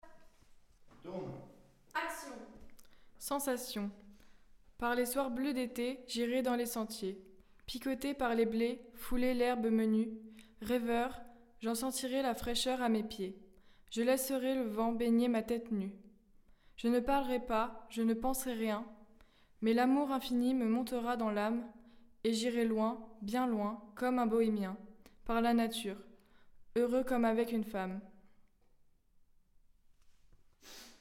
Lorsqu’un visiteur touche du doigt un poème, une lecture sonore mise en musique se déclenche.